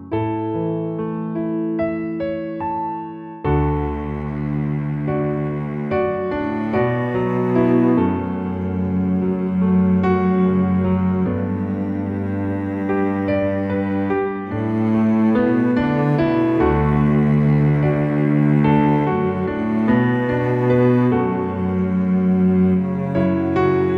Original Key